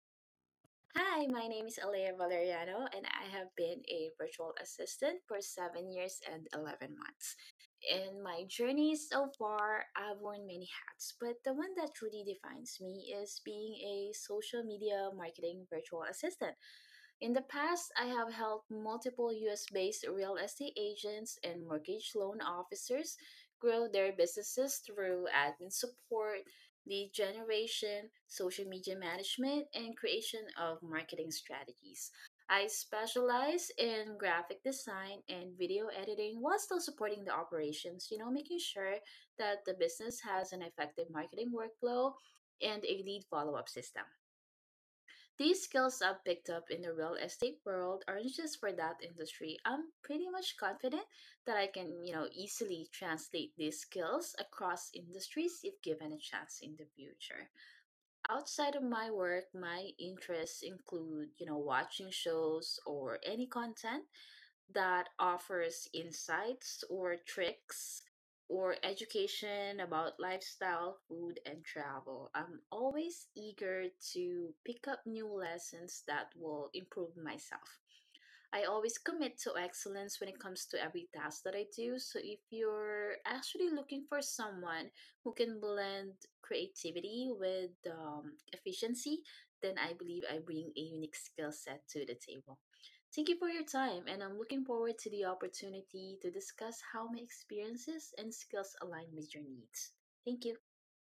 Self Introduction
Voice-Intro-Recording.mp3